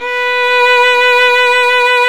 Index of /90_sSampleCDs/Roland L-CD702/VOL-1/STR_Violin 2&3vb/STR_Vln2 mf vb
STR  VL B 5.wav